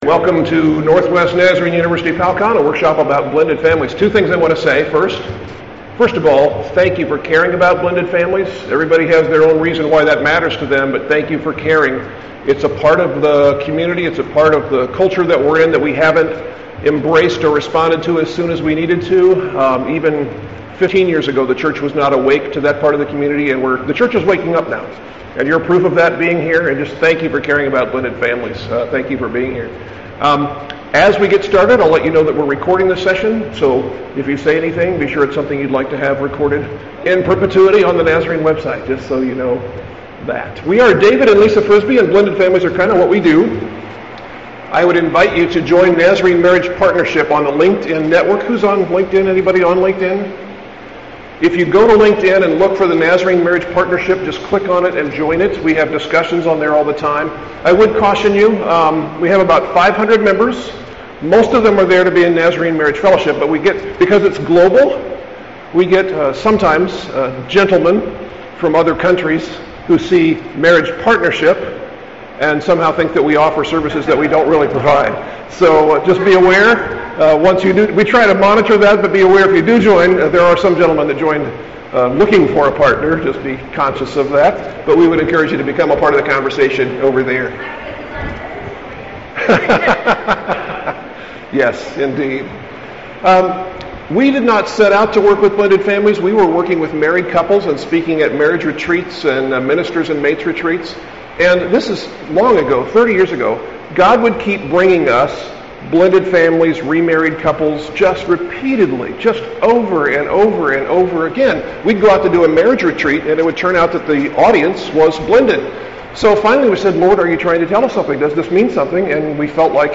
Gleaning from more than two decades of working with blended families and stepfamilies, this workshop explores ways churches and congregations can minister to today’s fractured families. Discover strategies to reach and include these families in your church fellowship.